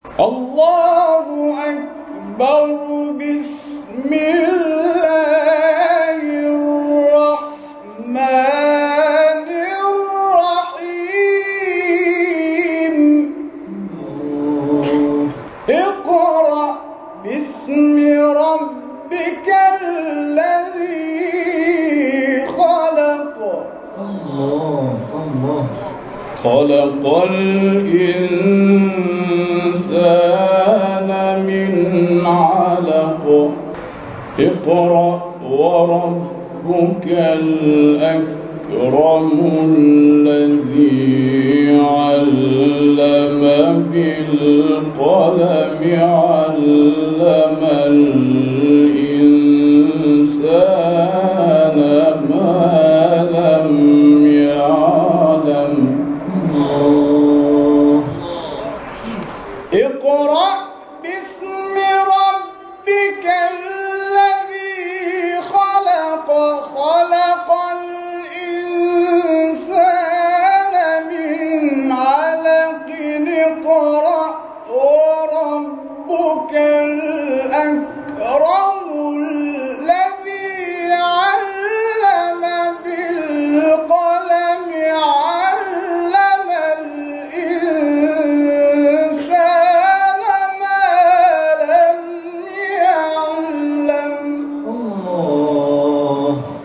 گروه فعالیت‌های قرآنی: جدیدترین مقاطع صوتی تلاوت شده توسط قاریان ممتاز کشور را می‌شنوید.
مقطعی در مقام بیات